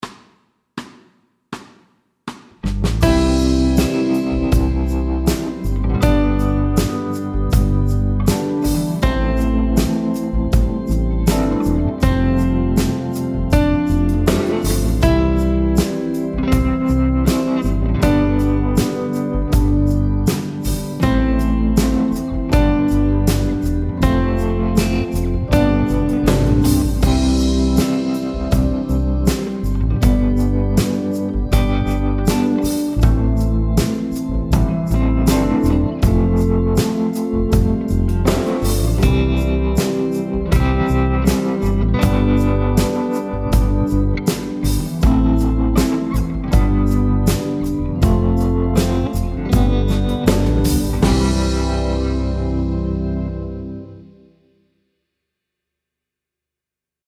Fast C instr (demo)
Rytmeværdier: 1/1- og 1/2 noder.